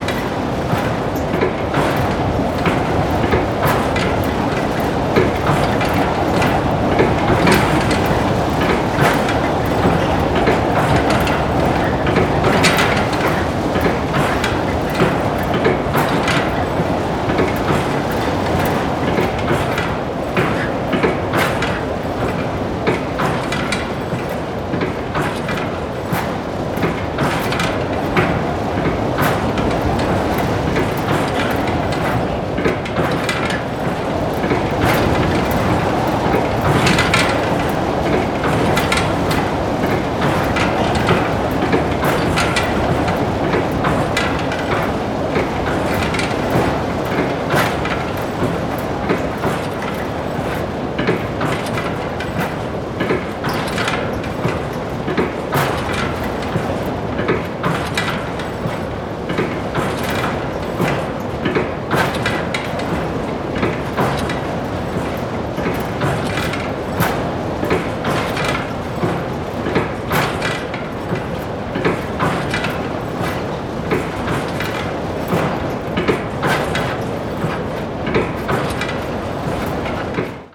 Звуки турбины самолета
Звук неисправного турбореактивного двигателя самолета